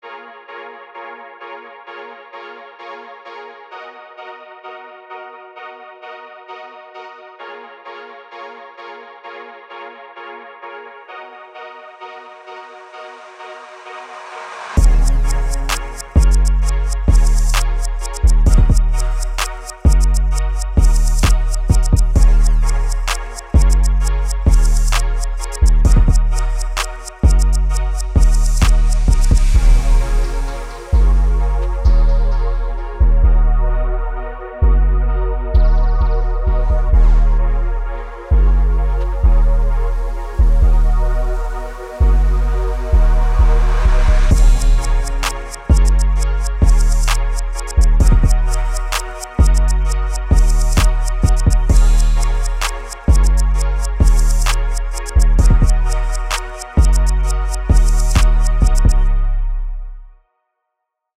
trap-infused hip-hop drawn from R&B and Pop influences
130 bpm
2. Main Synth
5. 808 bass
6. Strings/Vocals